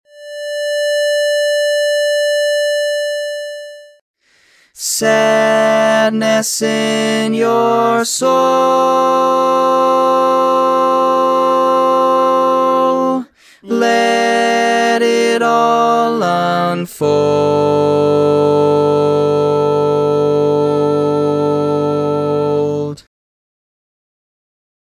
Key written in: D Minor
Learning tracks sung by